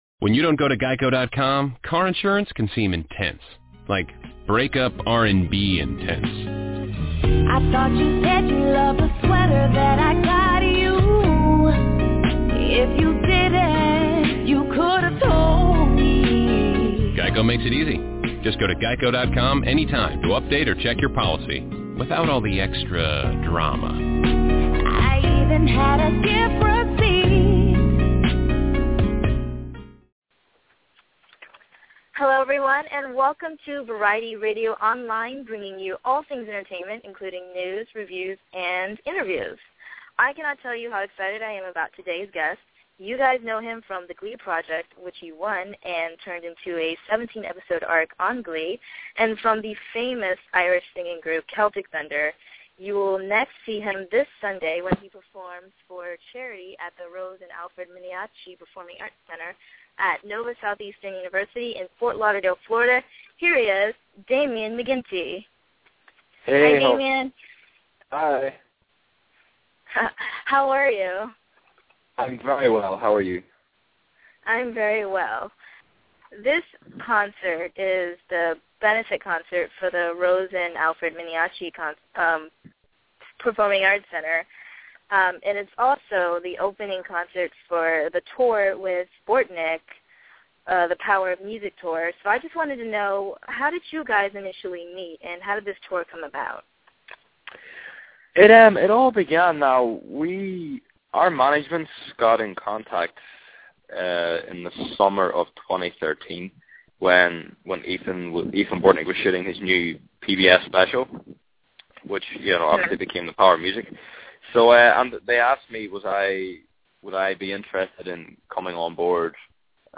Damian McGinty – Interview